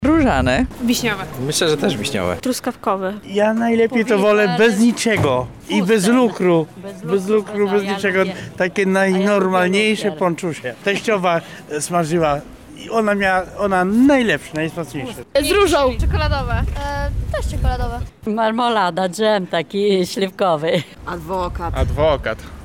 [SONDA] Jakie pączki Lublinianie lubią najbardziej?
O swoich preferencjach smakowych opowiadają lublinianie.